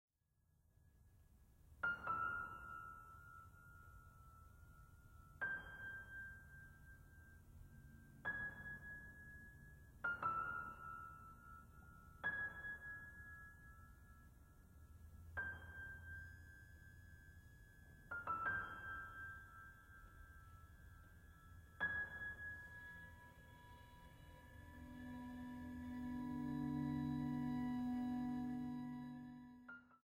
Música de Cámara